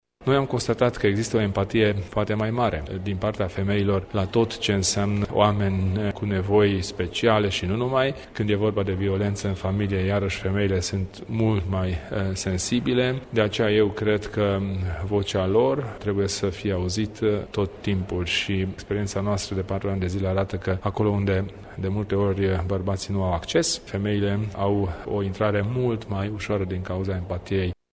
Aceasta a început la ora 10,00 în Sala mică a Palatului Culturii din municipiu în prezența mai multor personalități.
Președintele UDMR, Kelemen Hunor, prezent la eveniment, a vorbit despre importanța acestei Organizații în viața publică și politică: